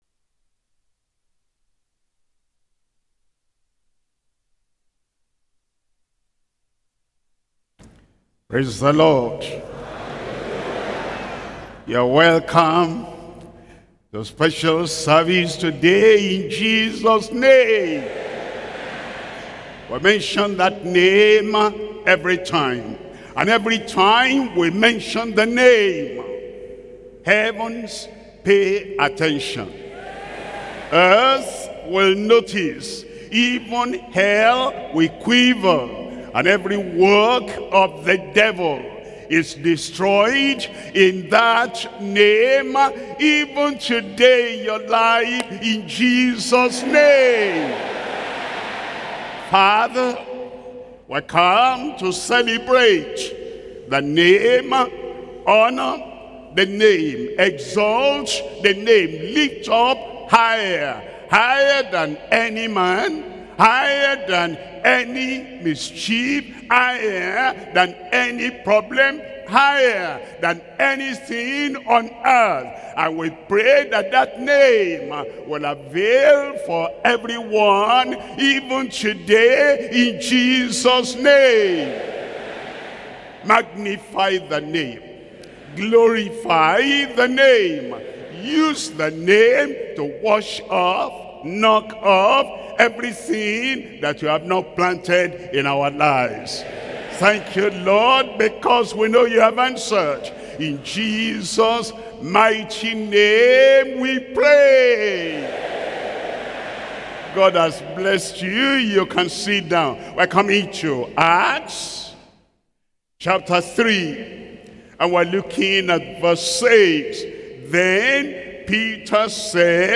Sermons - Deeper Christian Life Ministry
Worship Service